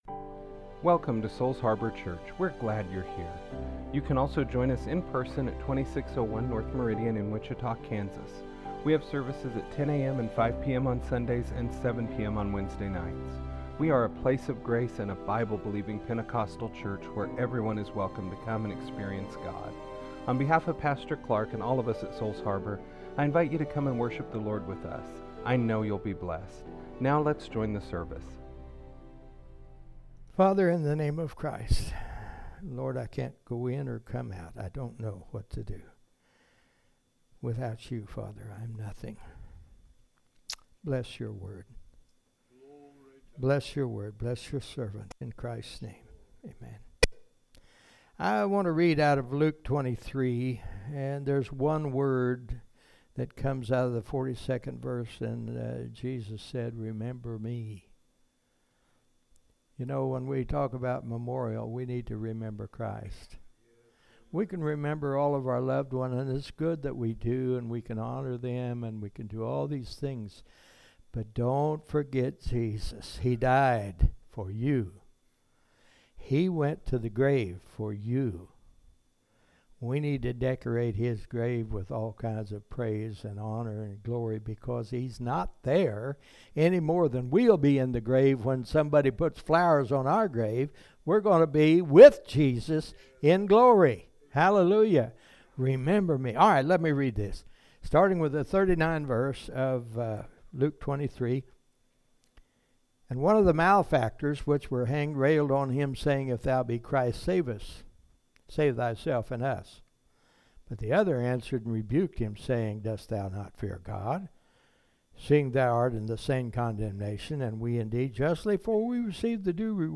Memorial Day Message